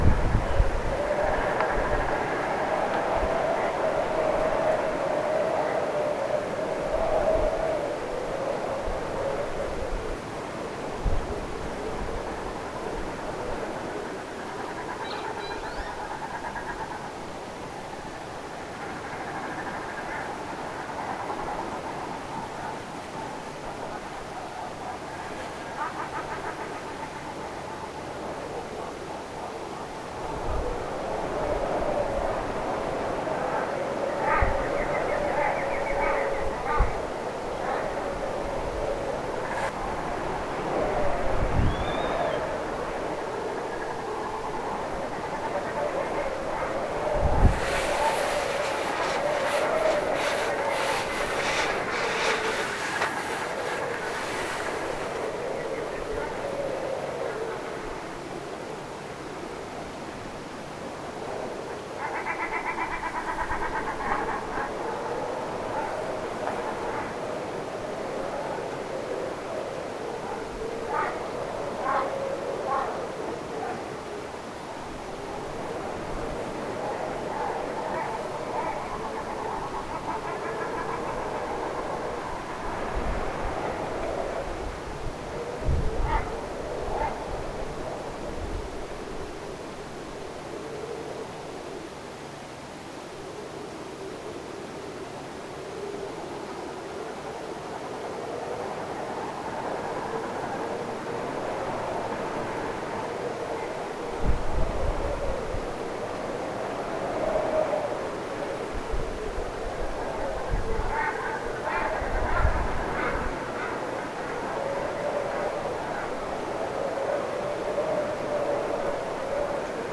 Niiden ääntelyä kuului.
ristosorsien ääntelyä, tuulee
ristisorsien_aantelya_tuulee.mp3